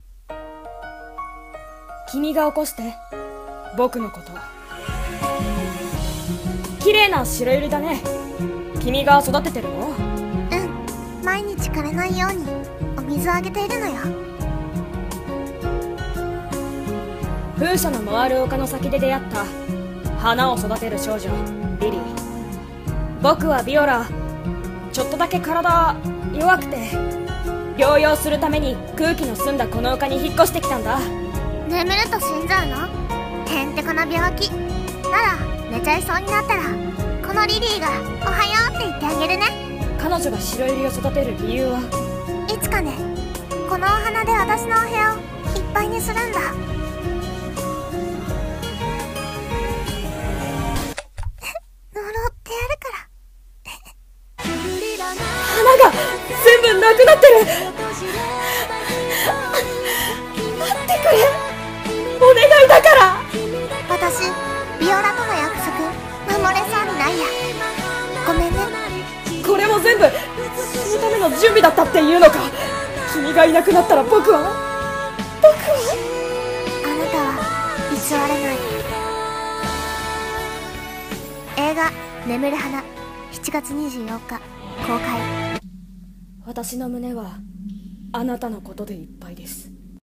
CM風声劇「眠る花」